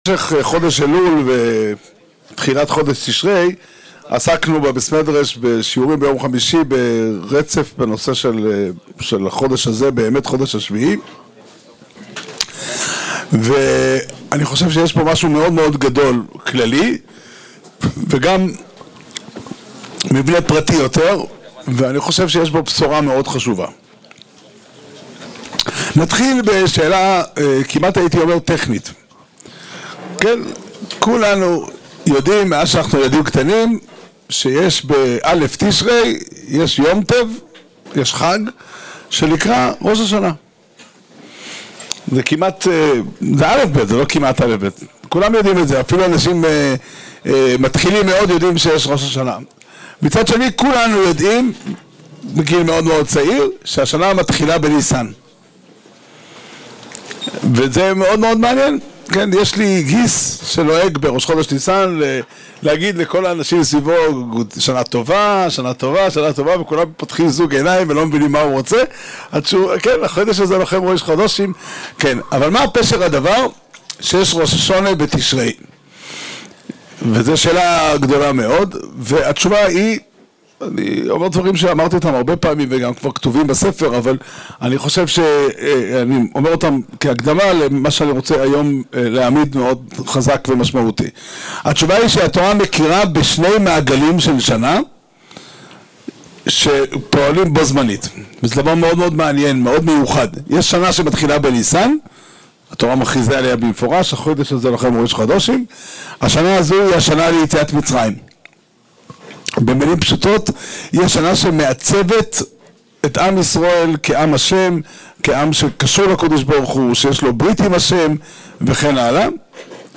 השיעור נמסר במסגרת הלימוד השנתי בליל הו"ר תשפ"ה בסוכה בהר נוף